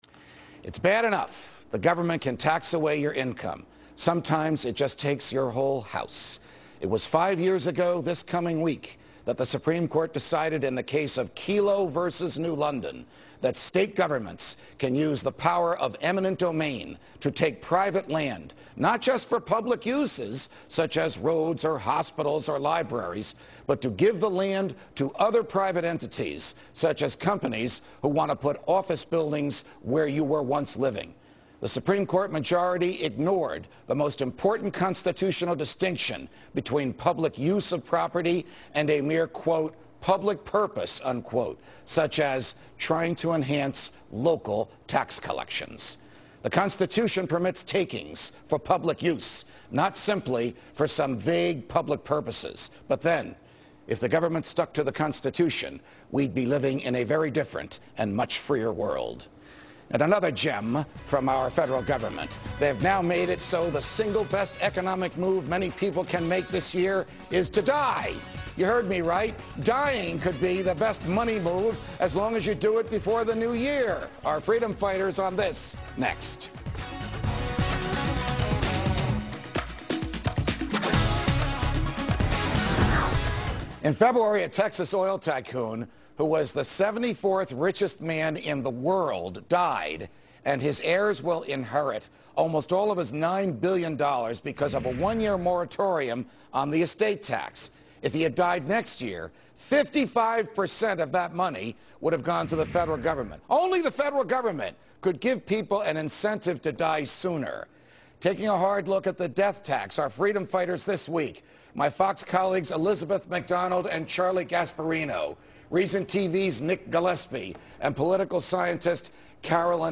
On June 16, 2010 Reason's Nick Gillespie appeared on Fox News Freedom Watch with Judge Andrew Napolitano to discuss estate taxes, Barack Obama's green-energy agenda, and the BP oil spill.